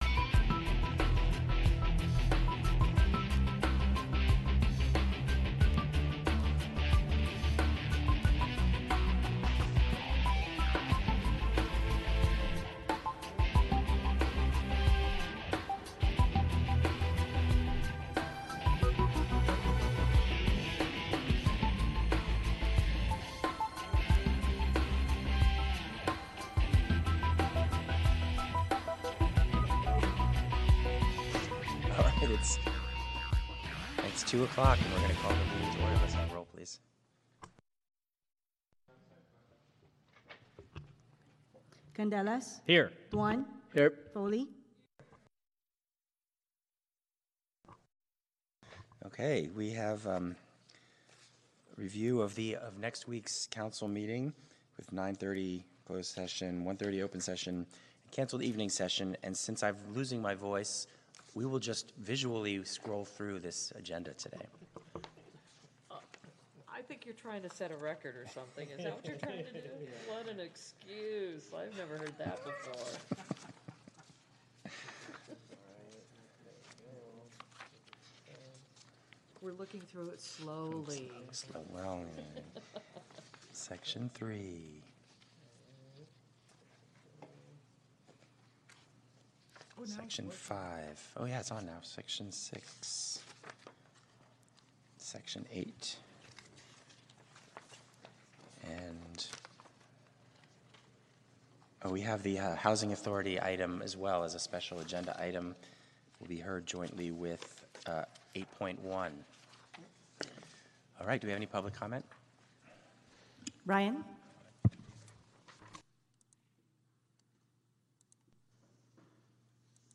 You'll hear authentic audio capturing the voices of city officials, community leaders, and residents as they grapple with the local issues of the day. This podcast serves as an archival audio record, providing transparency and a direct line to the workings of local government without editorial polish.